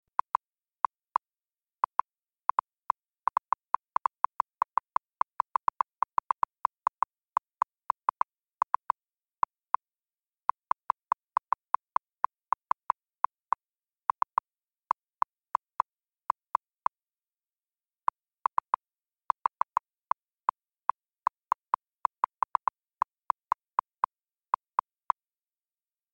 جلوه های صوتی
دانلود صدای تایپ 11 از ساعد نیوز با لینک مستقیم و کیفیت بالا
برچسب: دانلود آهنگ های افکت صوتی اشیاء دانلود آلبوم صدای تایپ کردن از افکت صوتی اشیاء